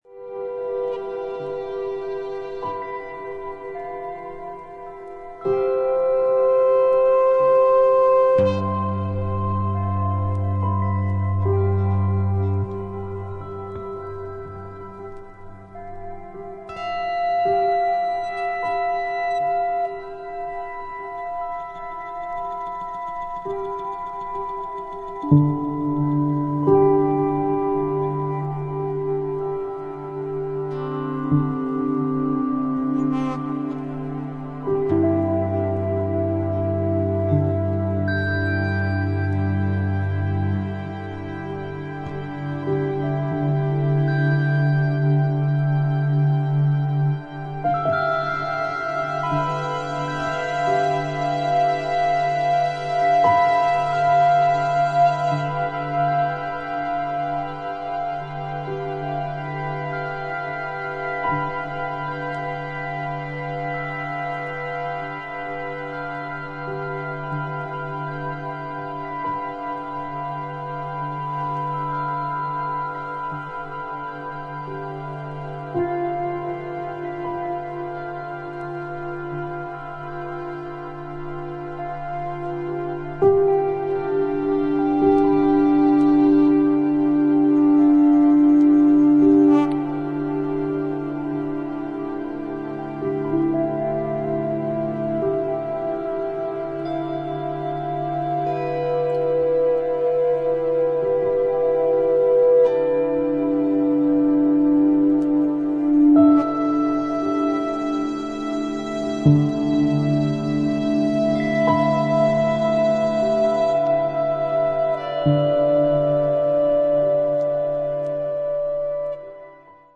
ピアノやヴァイオリン、ヴィオラ、ムーグギター等、穏やかで温かみのある楽器の音色をベースに